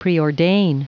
Prononciation du mot preordain en anglais (fichier audio)
Prononciation du mot : preordain